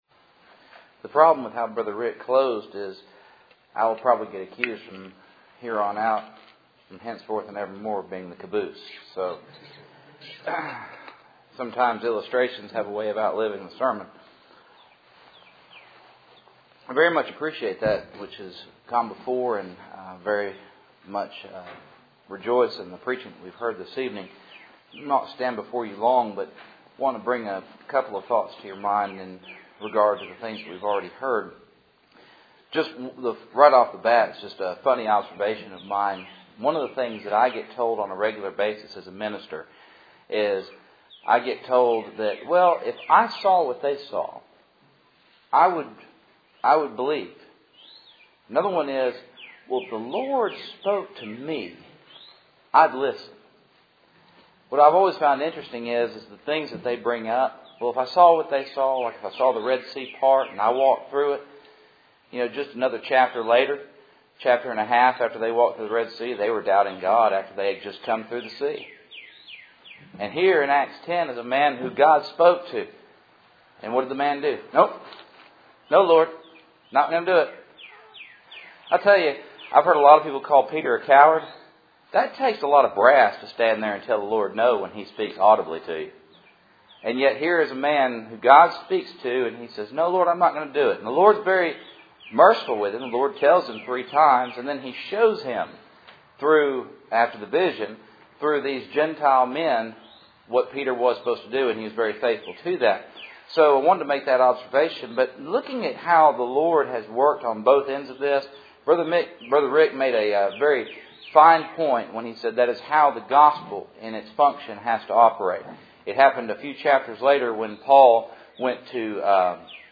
Cool Springs PBC Sunday Evening